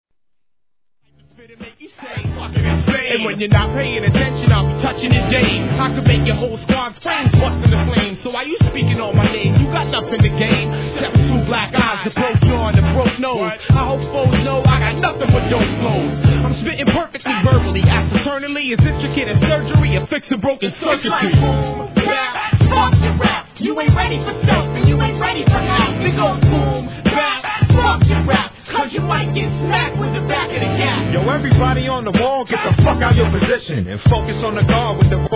HIP HOP/R&B
NEW YORK UNDERGROUND SHIT!!!